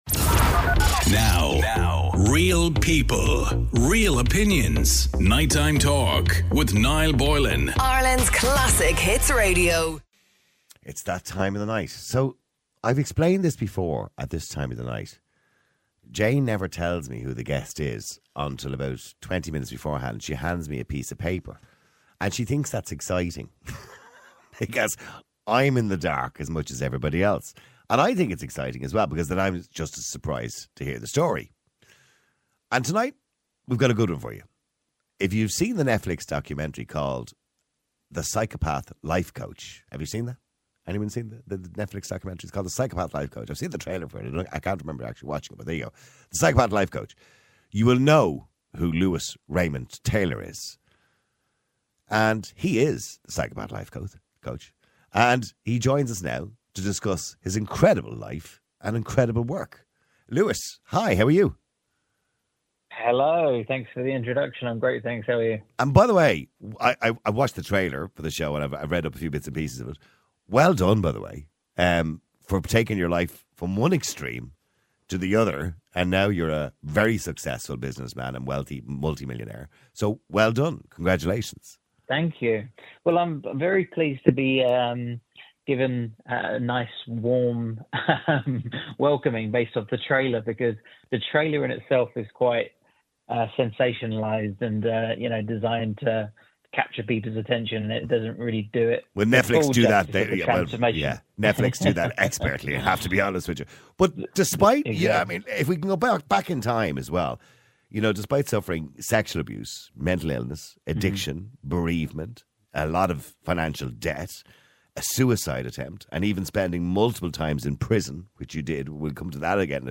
News Talk News Radio Station